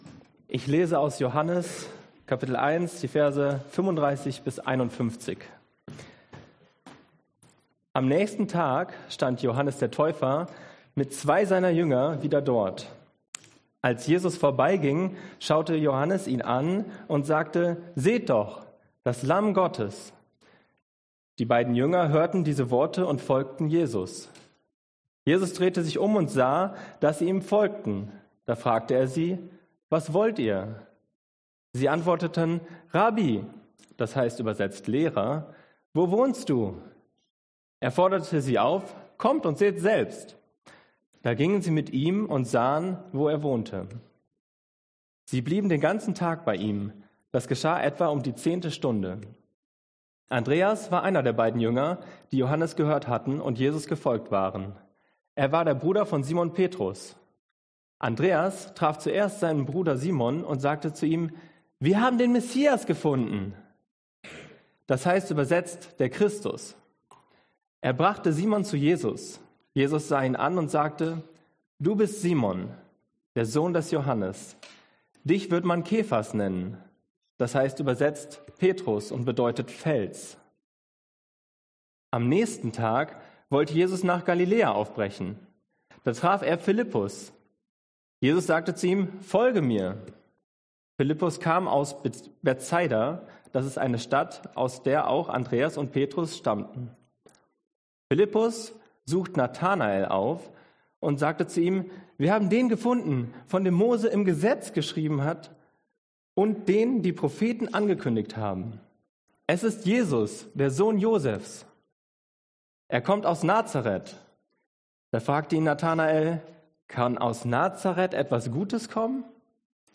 Passage: Johannes 1,35-51 Dienstart: Predigt